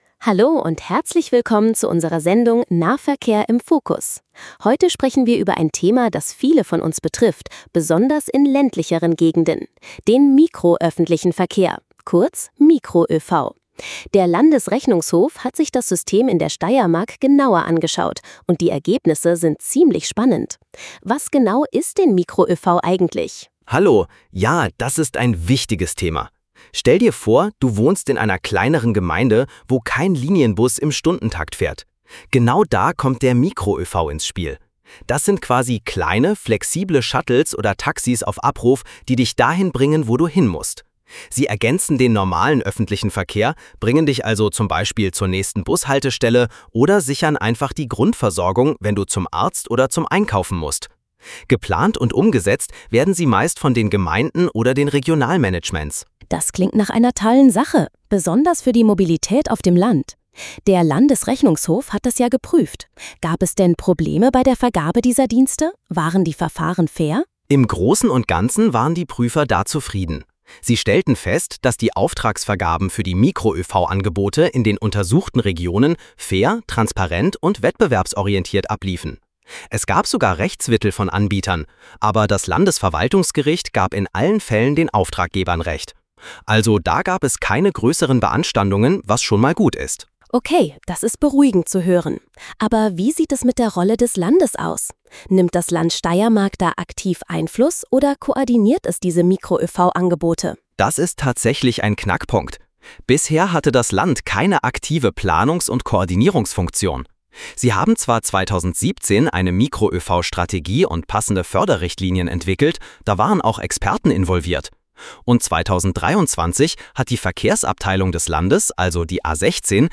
*Der Podcast wurde vom Landesrechnungshof Steiermark autorisiert und mit "AIPodify" erstellt.